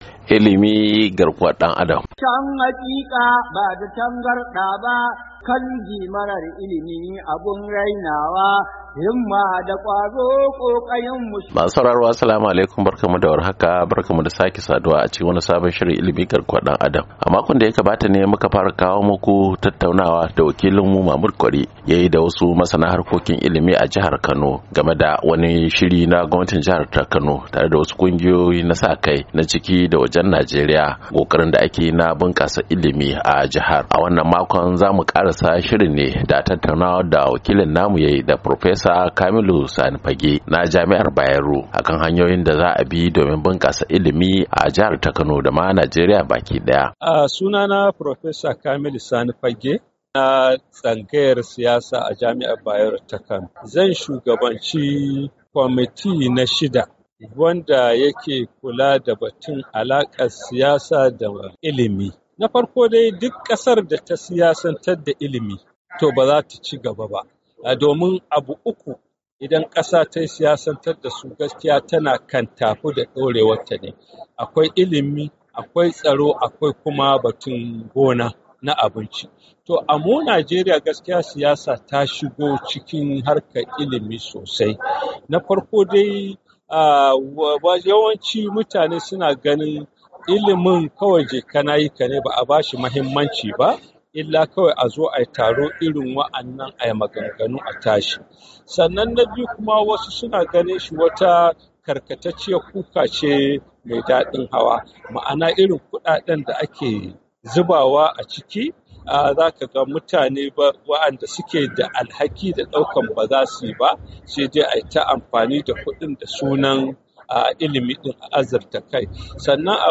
A shirin Ilimi na wannan makon mun ci gaba ne da tattaunawa da masana akan yadda Gwamnatin jihar kano ta gudanar da wani taro na masu da ruwa da tsaki domin inganta harkokin Ilimi a jihar da yanzu haka ke fama da yaran da ba sa zuwa makaranta fiye da miliyan daya.